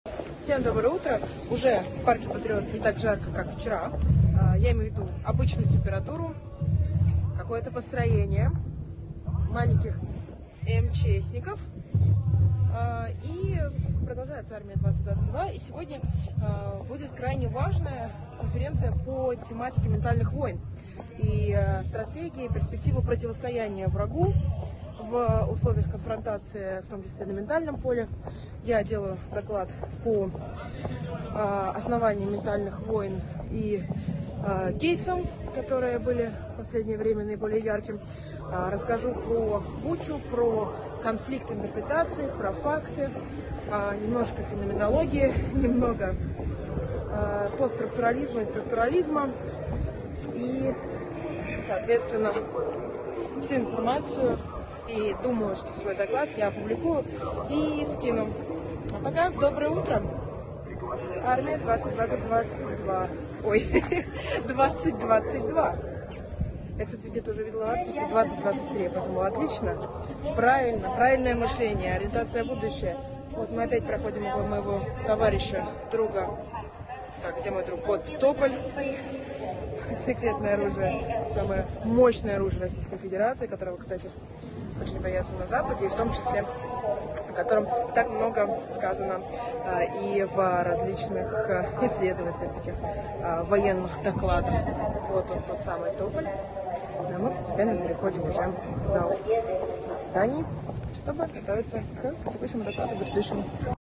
Репортаж Даши Дугиной с выставки "Армия 2022"